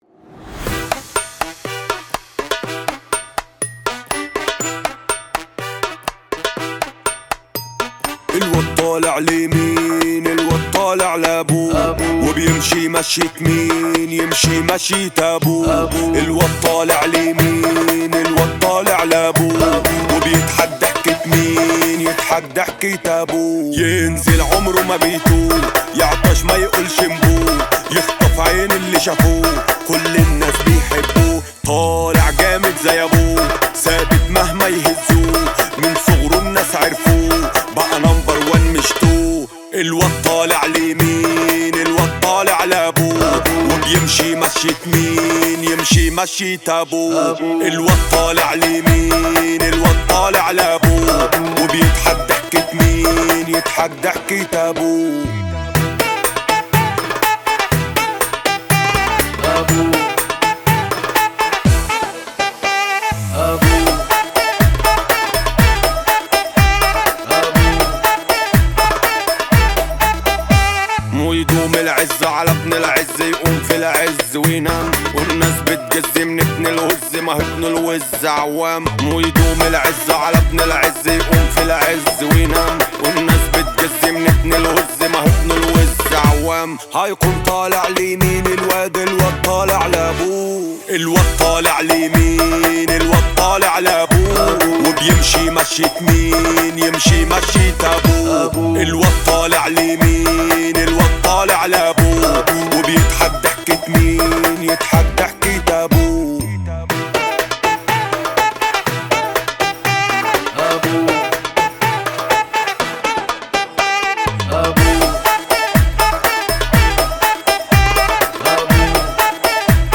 اغانى شعبي مهرجانات